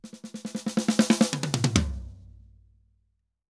Drum_Break 136-1.wav